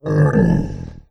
SFX
Monster_Hit2.wav